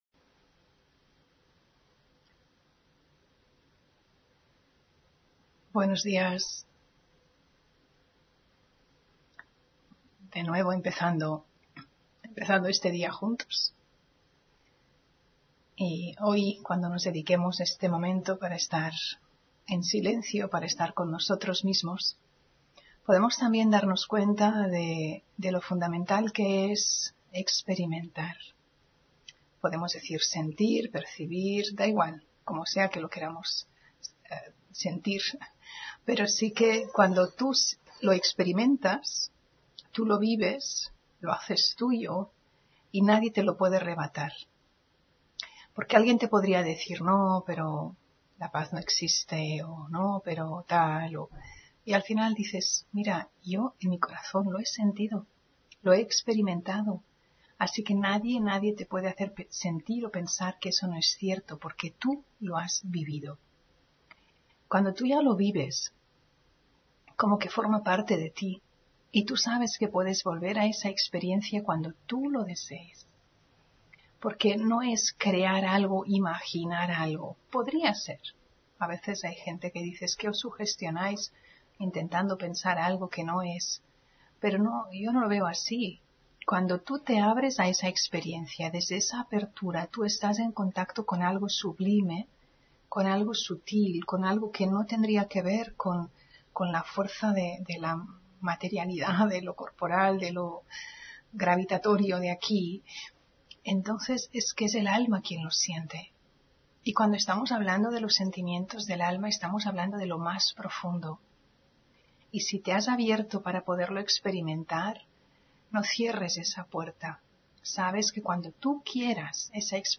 Meditación y conferencia: La relación entre el alma y el Alma Suprema (14 Junio 2023)